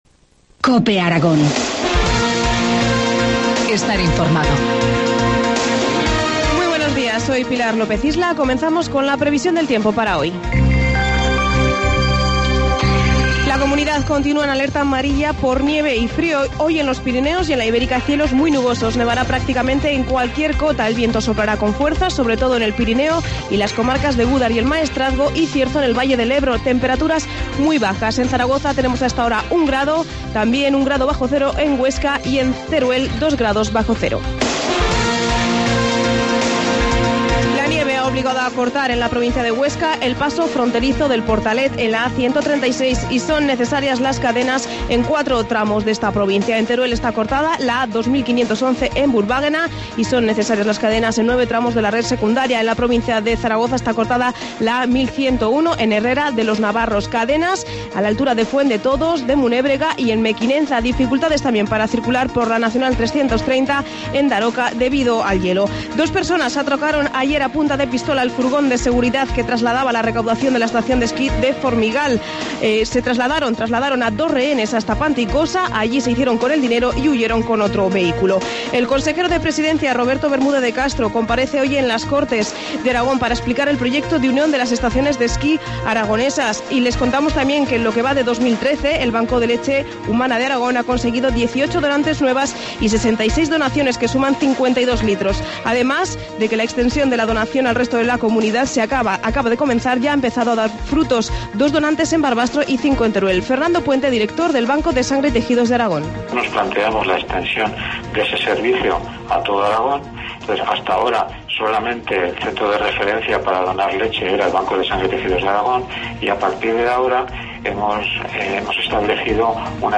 Informativo matinal, lunes 25 de abril, 8.25 horas